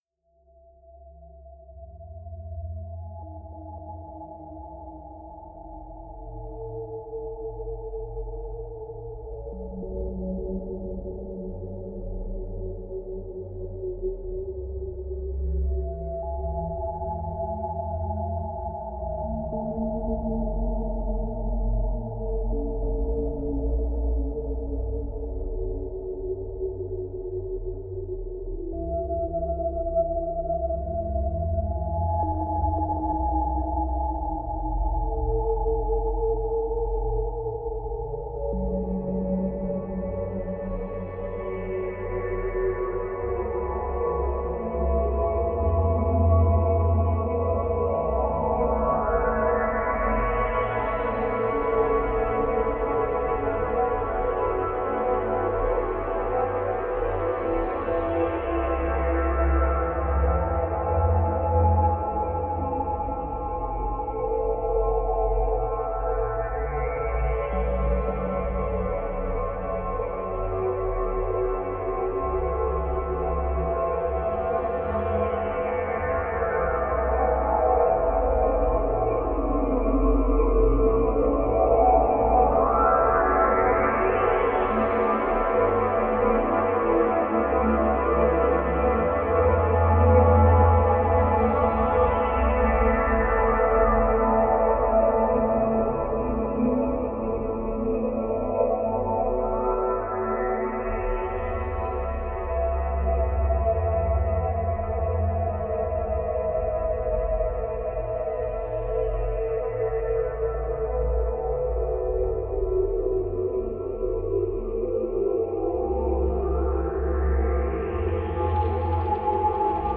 Reimagined piece from St. Michaelis, Hamburg